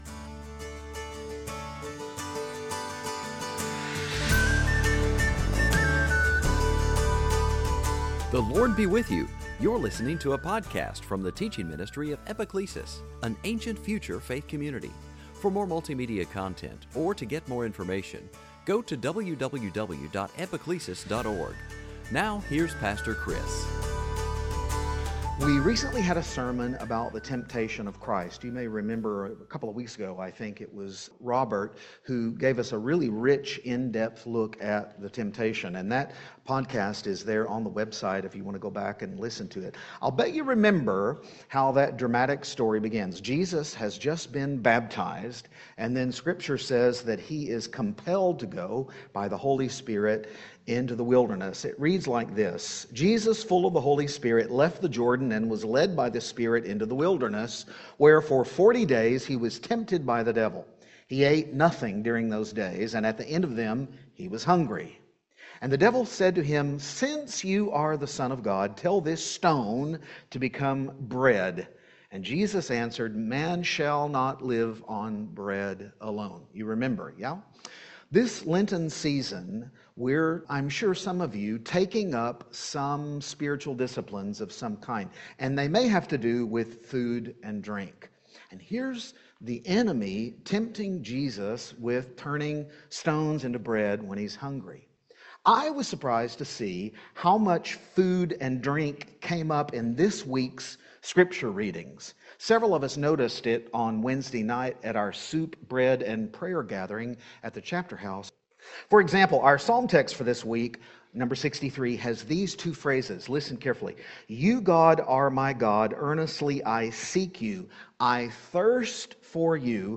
Sunday Teaching Passage